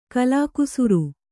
♪ kalākusuru